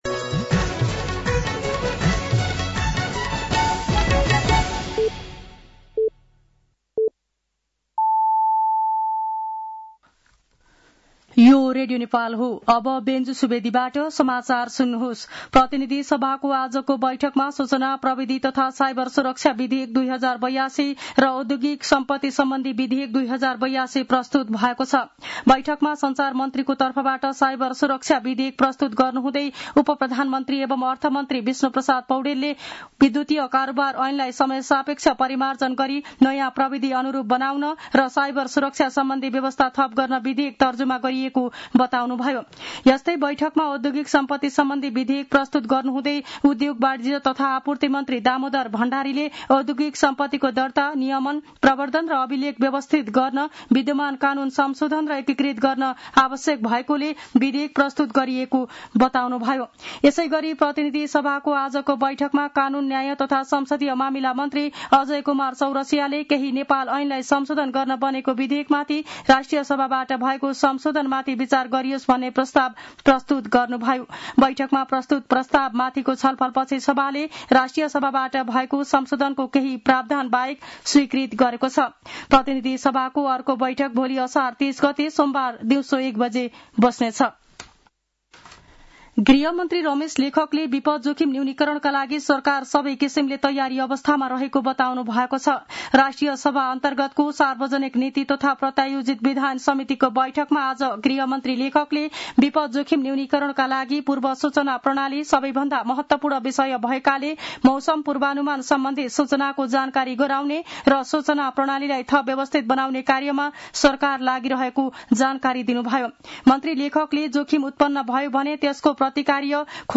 साँझ ५ बजेको नेपाली समाचार : २९ असार , २०८२
5-pm-nepali-news-3-29.mp3